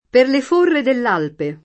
forra
forra [ f 1 rra ] s. f.